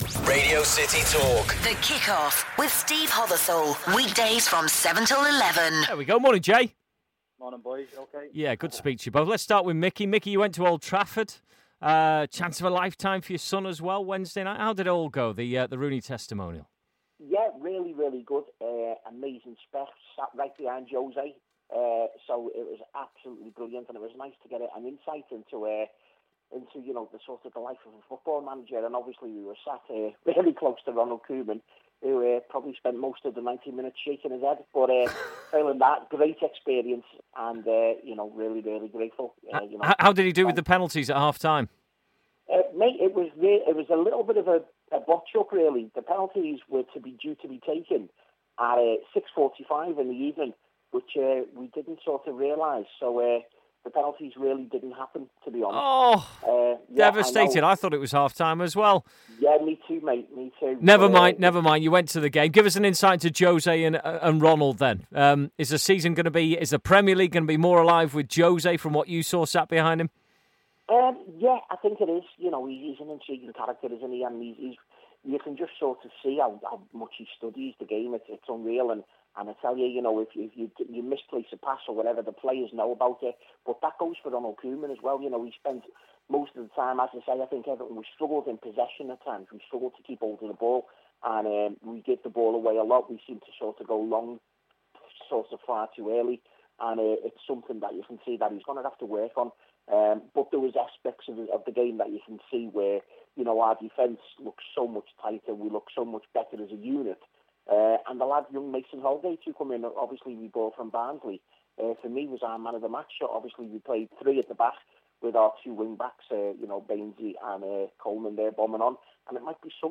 Liverpool Fan
Everton Fan